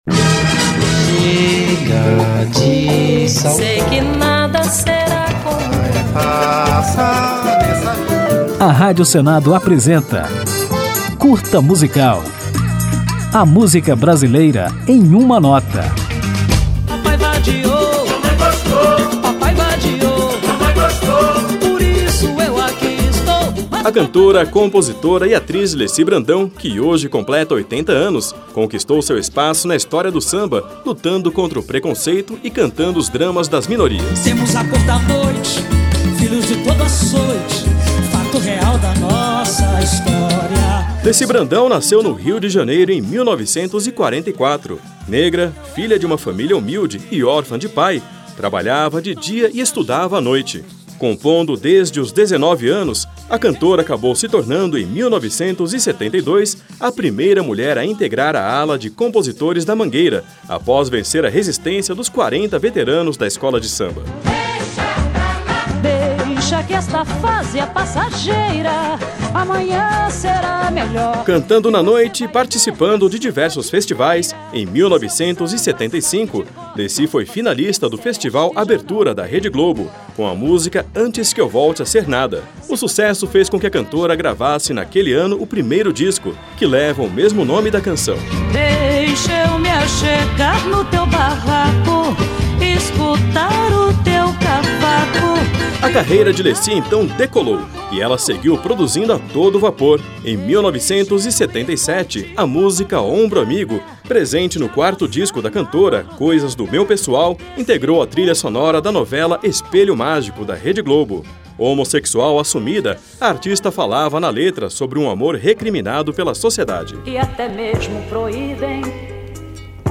Para comemorar, o Curta Musical presta uma homenagem à artista e apresenta um pouco da obra desta grande sambista, conhecida pelo engajamento, pelas letras de temática social e pelo samba de altíssima qualidade. Ao final do programa ouviremos Zé do Caroço, um clássico de Leci Brandão que nunca sai de moda.
Samba